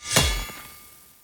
melee-hit-7.ogg